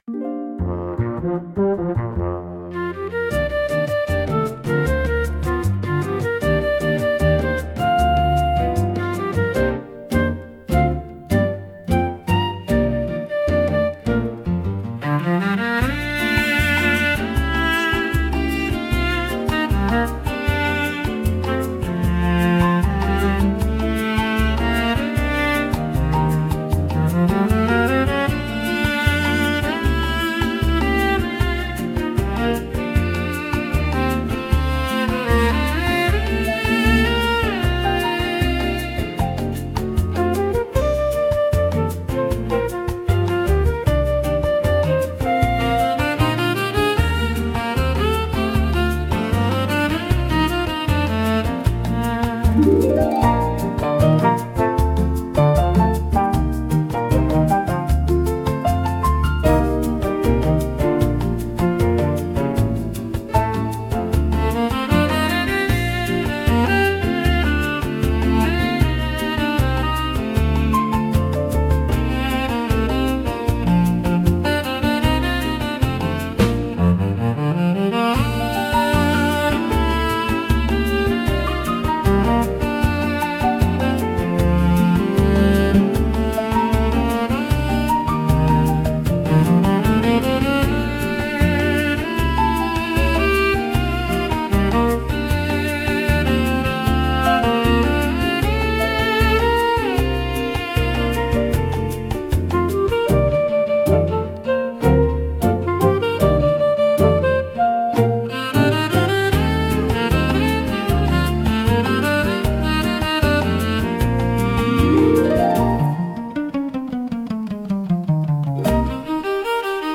música e arranjo: IA) instrumental